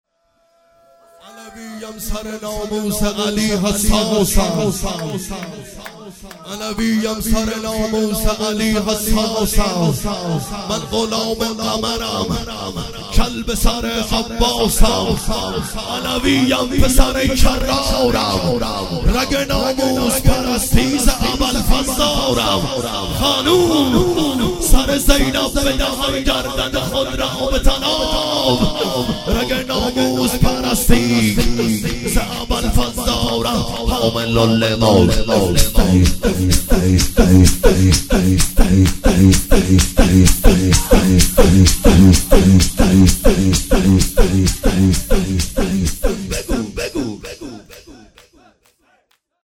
رجز